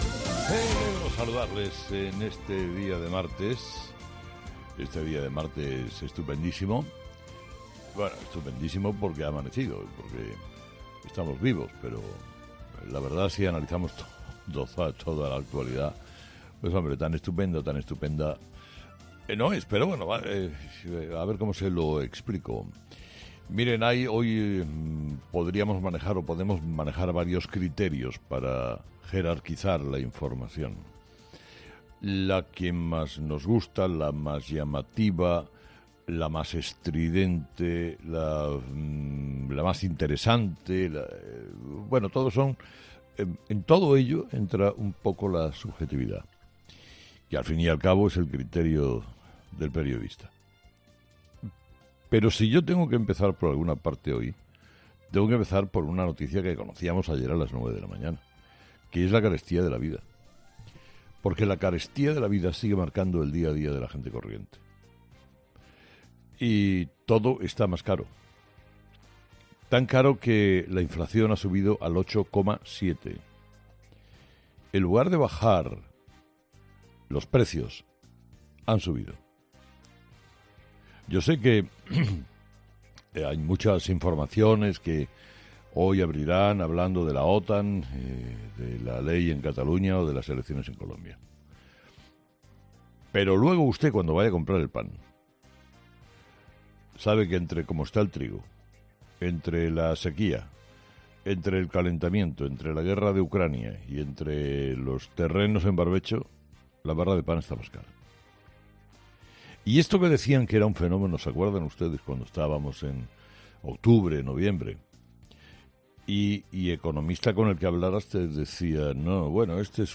Carlos Herrera, director y presentador de 'Herrera en COPE', ha comenzado el programa de este martes analizando las principales claves de la jornada, que pasan, entre otros asuntos, por el dato del IPC adelantado de mayo conocido este lunes y el decreto de la Generalitat sobre la sentencia del 25%.